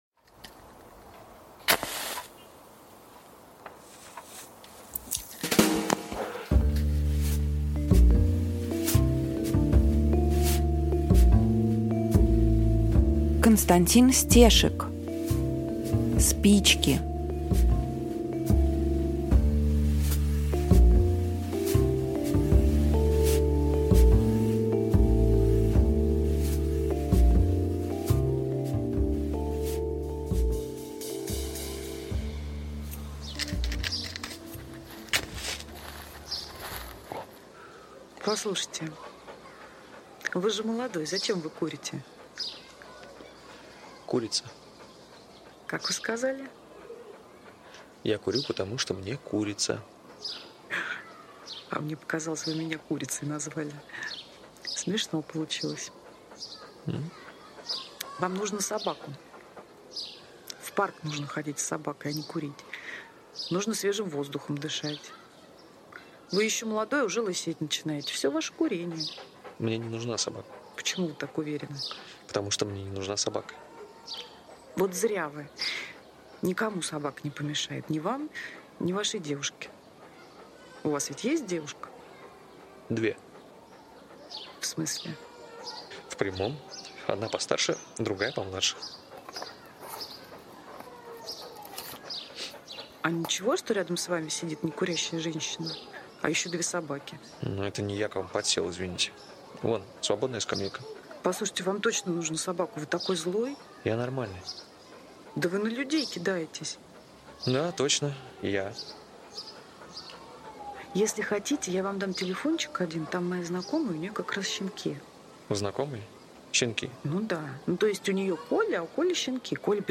Aудиокнига Спички